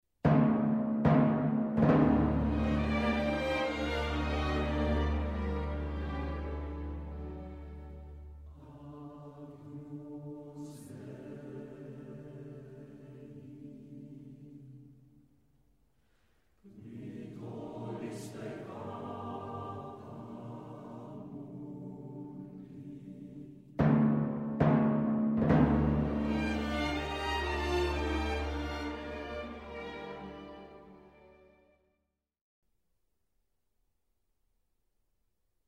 soprán
alt
tenor
varhany